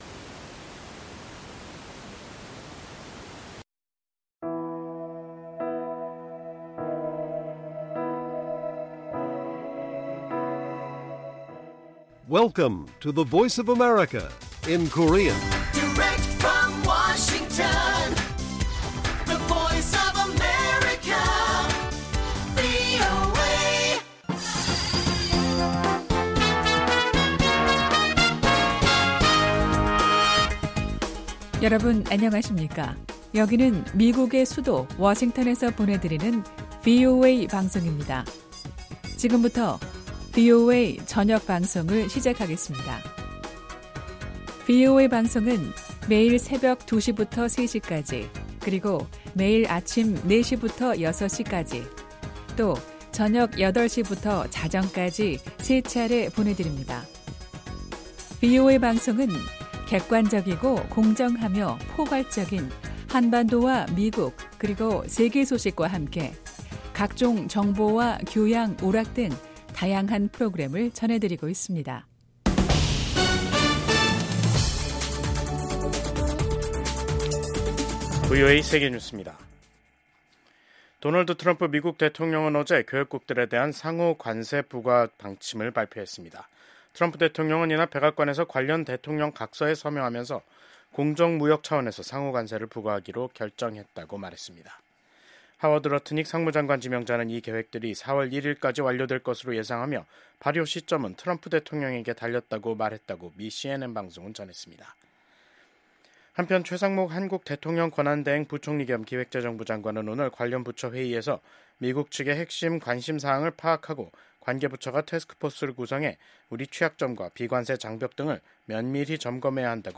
VOA 한국어 간판 뉴스 프로그램 '뉴스 투데이', 2025년 2월 14일 1부 방송입니다. 도널드 트럼프 미국 대통령이 러시아-우크라이나 전쟁 종전 협상 개시를 선언하면서 러시아에 파병된 북한 군 철수 문제도 논의될지 주목됩니다. 미국 국방장관이 중국의 위협에 맞서기 위한 한국 등 역내 동맹국과의 협력 의지를 재확인했습니다.